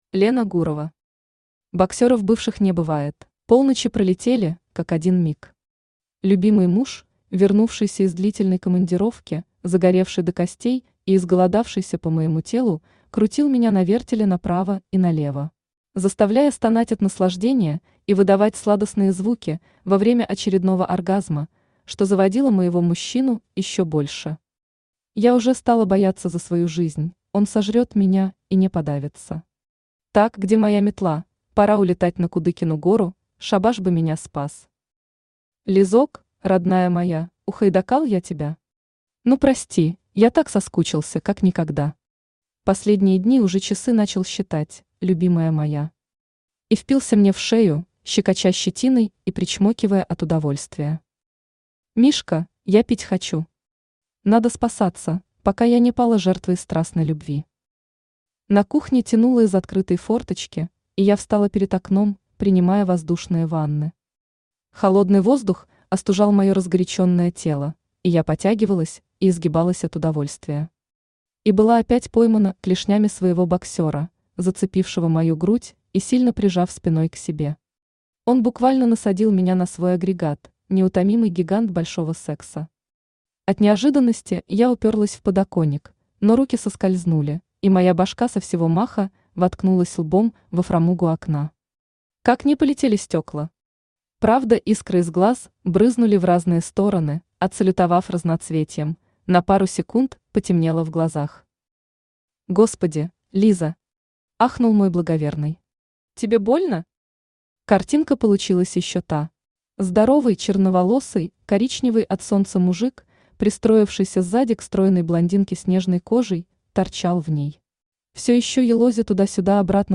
Аудиокнига Боксёров бывших не бывает!
Автор Лена Гурова Читает аудиокнигу Авточтец ЛитРес.